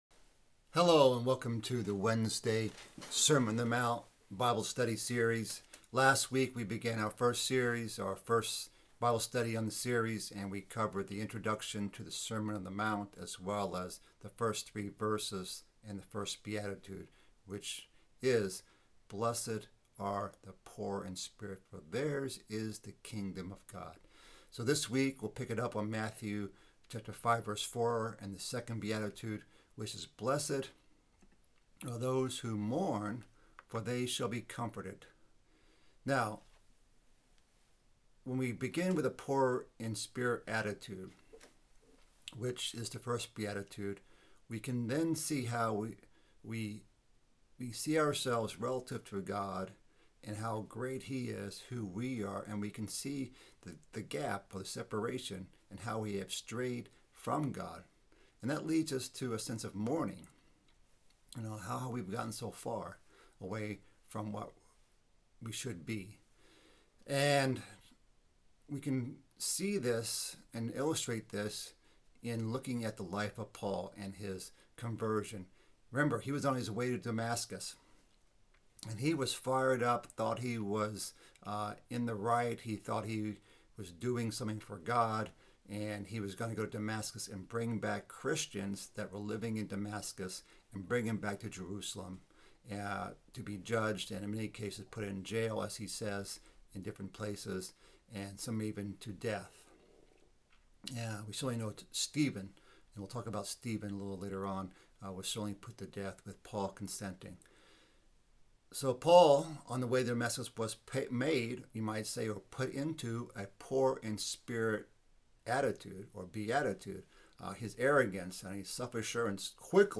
Bible Study - Sermon on the Mt. Part 2 - Matthew 5:4-7